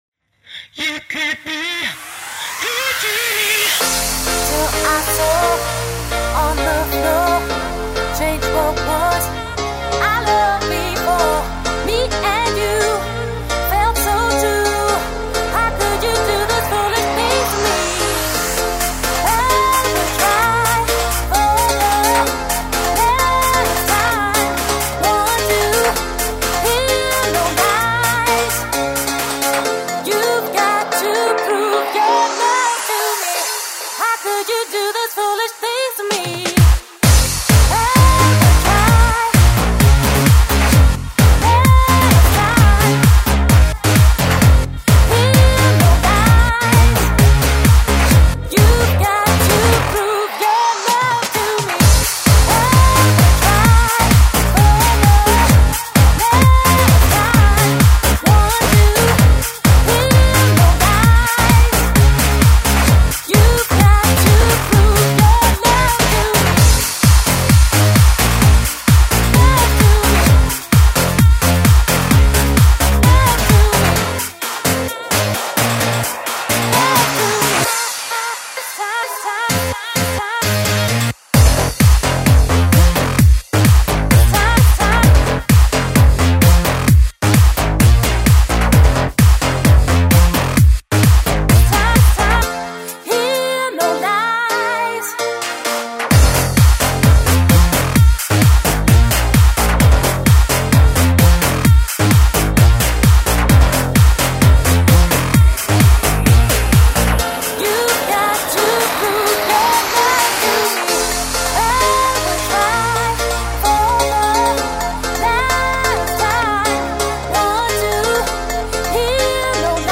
Жанр:Electro/House/